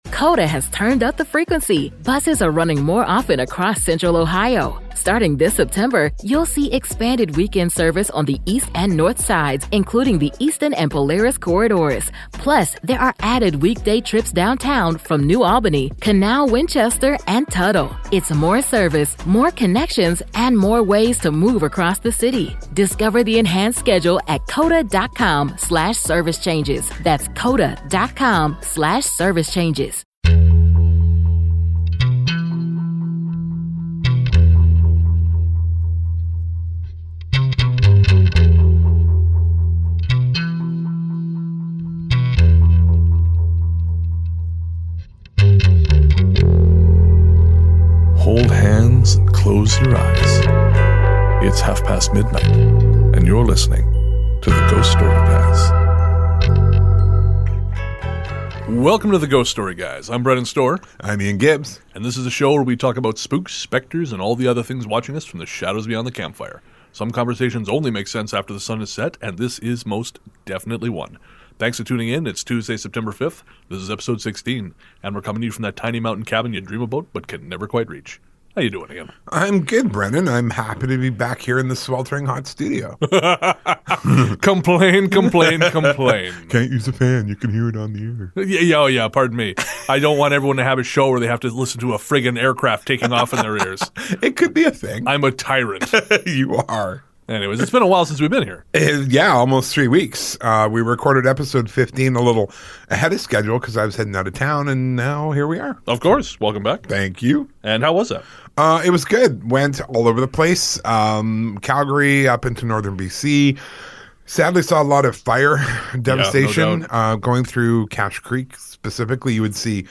And there is singing in those outtakes. Oh yes there is singing.